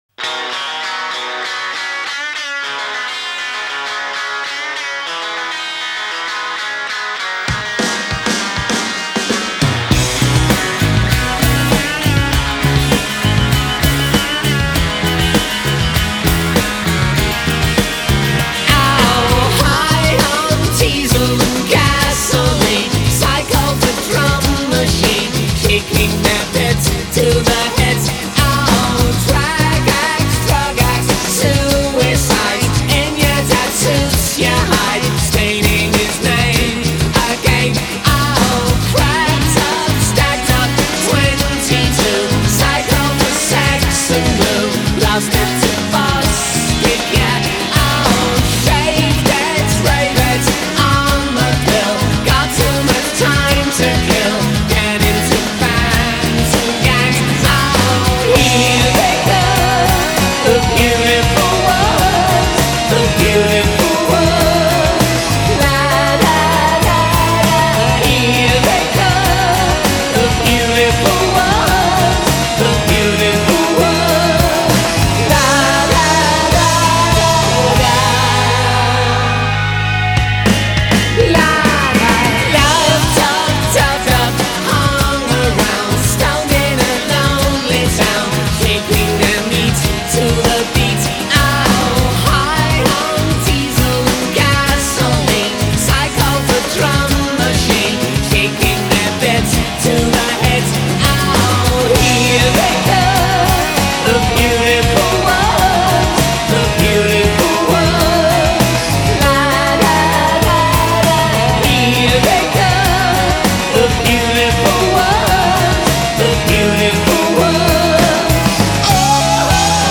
Style: Alt Rock